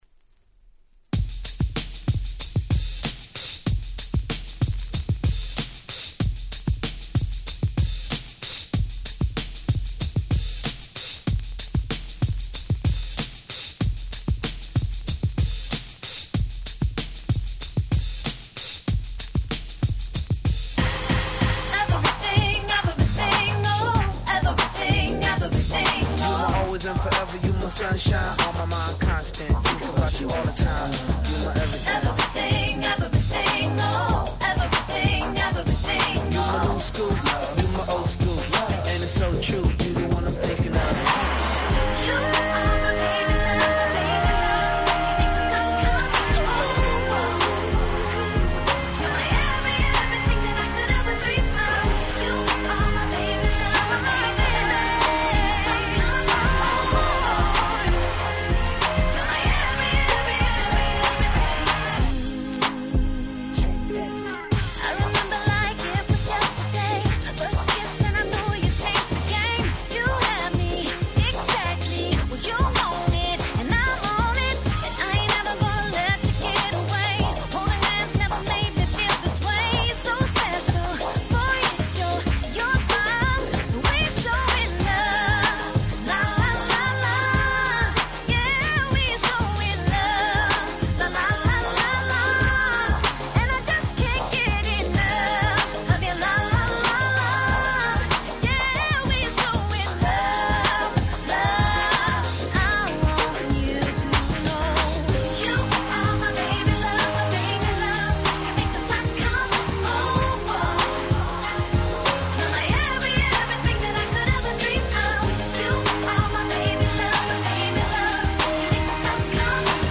07' Smash Hit R&B♪
キラキラ系 キャッチー系